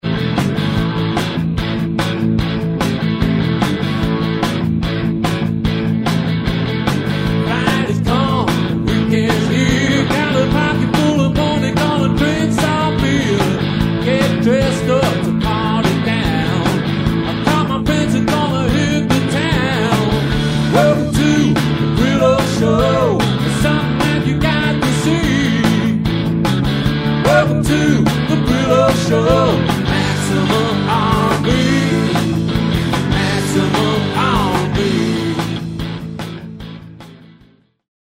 sweaty pounding rhythms